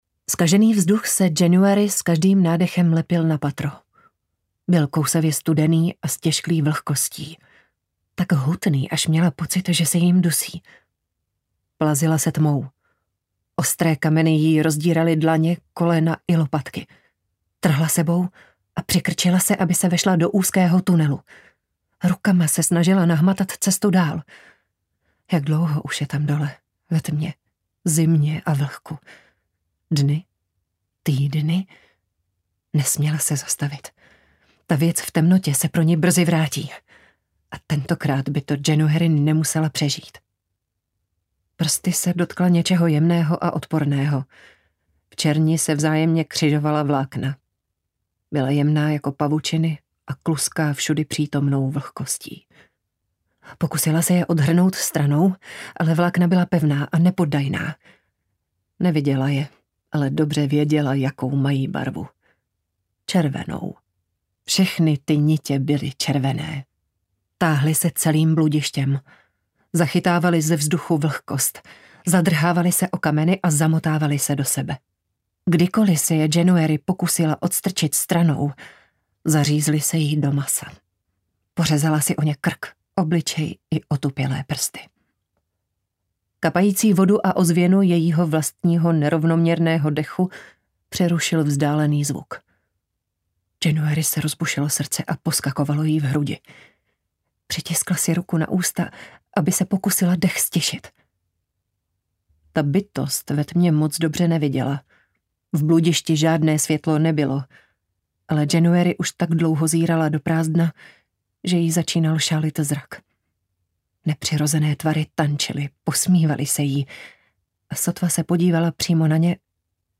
Kde tě nenajde audiokniha
Ukázka z knihy